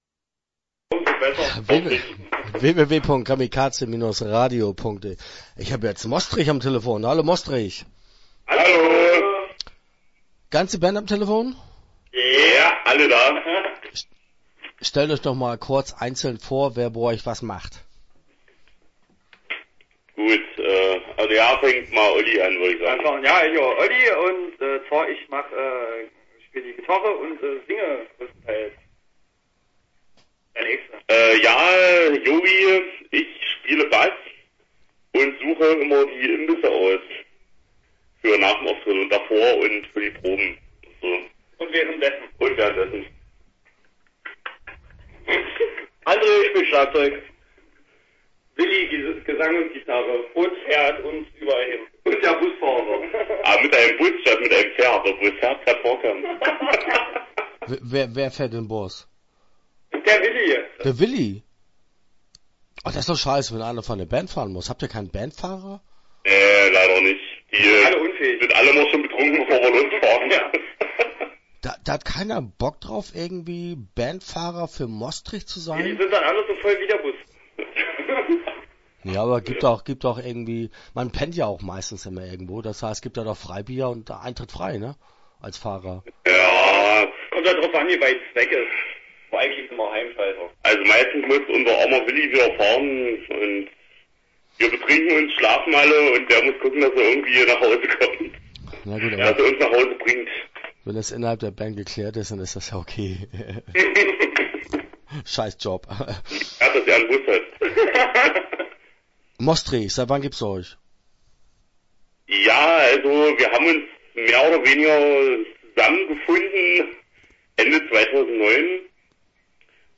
Mostrich - Interview Teil 1 (12:03)